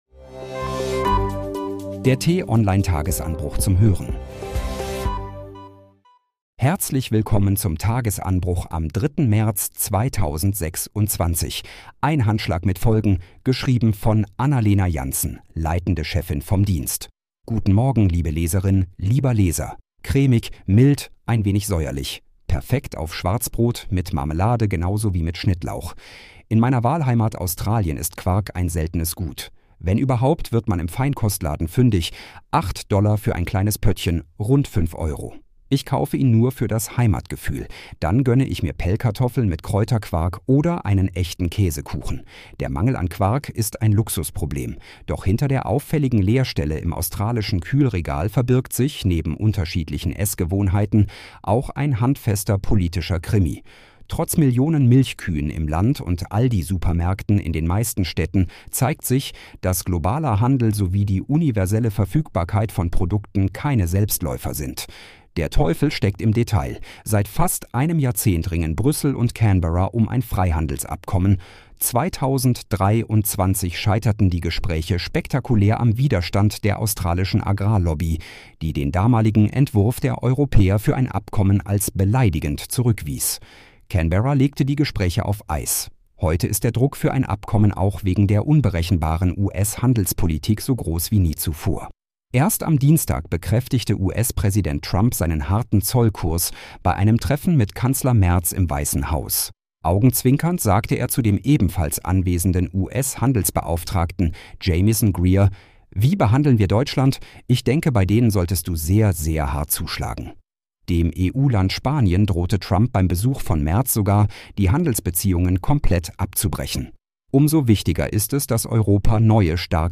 Den „Tagesanbruch“-Podcast gibt es immer montags bis freitags ab 6 Uhr zum Start in den Tag vorgelesen von einer freundlichen KI-Stimme – am Wochenende mit einer tiefgründigeren Diskussion.